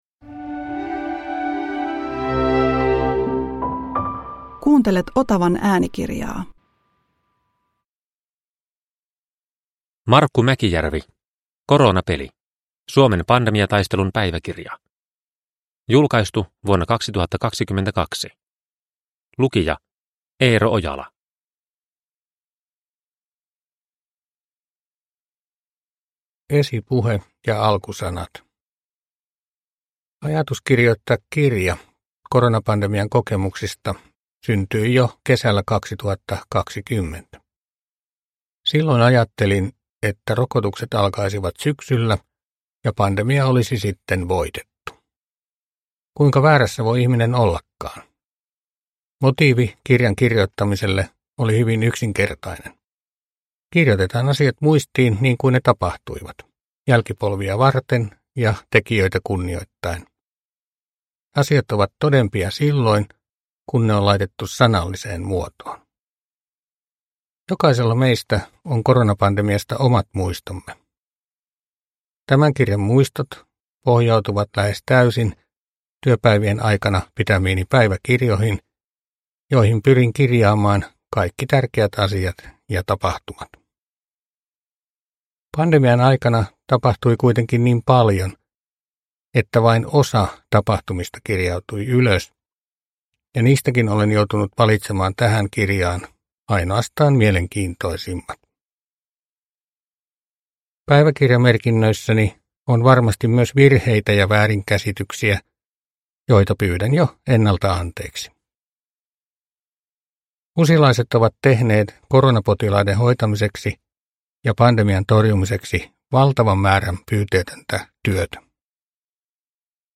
Koronapeli – Ljudbok – Laddas ner